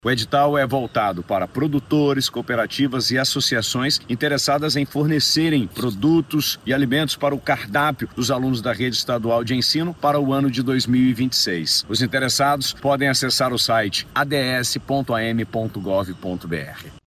A previsão é de mais de trinta e cinco milhões e setecentos mil reais investidos na aquisição dos alimentos, em parceria com a Secretaria de Educação e Desporto Escolar, como explica o governador Wilson Lima.